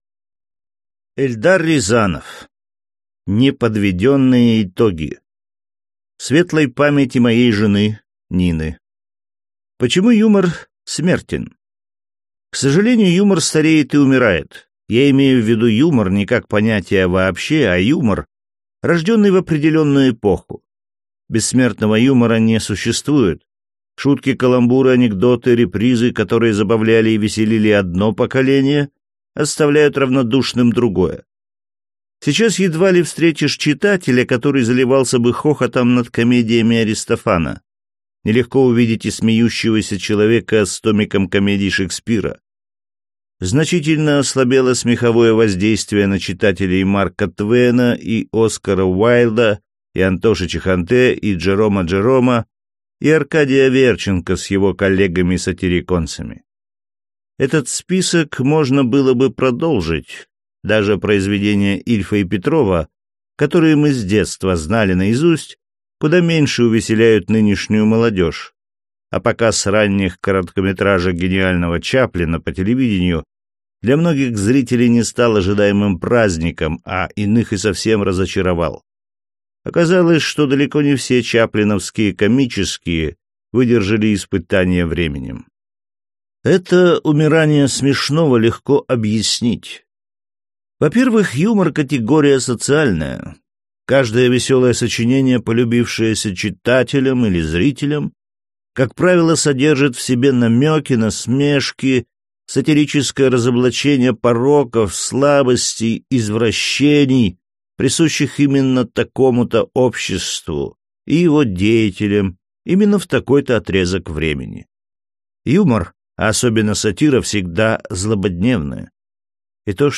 Аудиокнига Неподведенные итоги | Библиотека аудиокниг